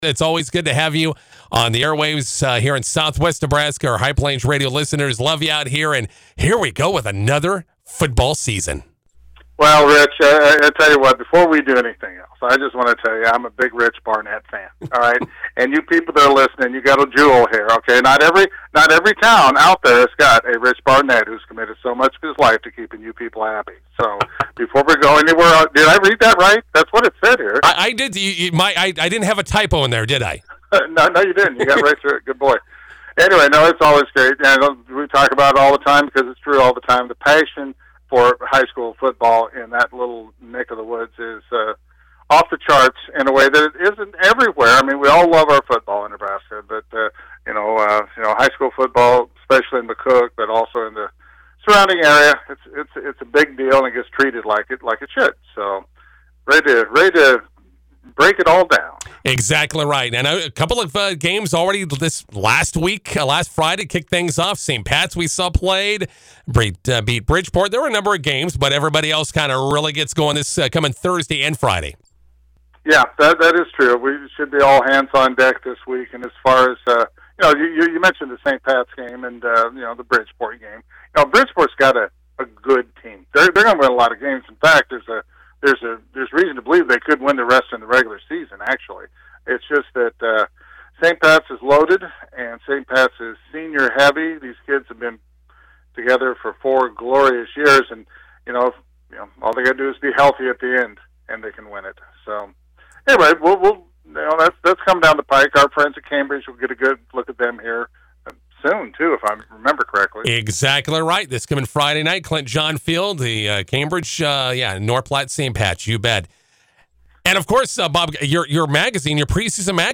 INTERVIEW: High School Football is back!!